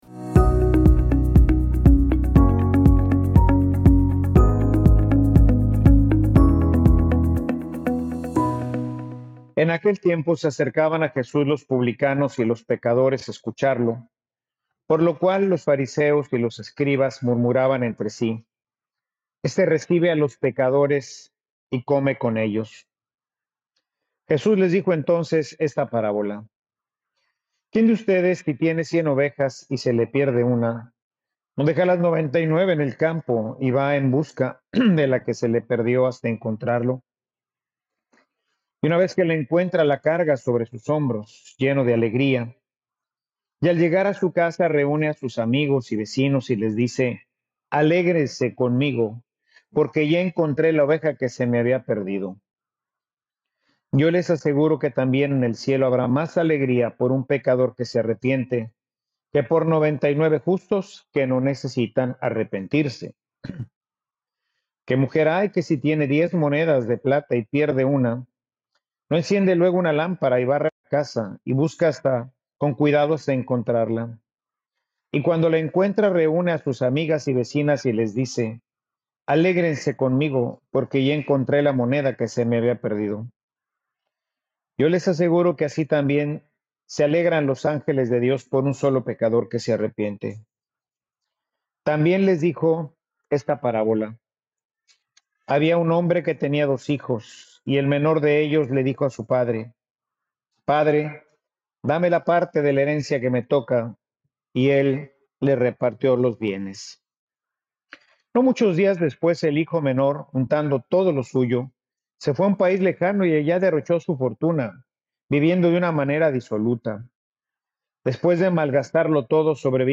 Homilia_Su_amor_nos_espera.mp3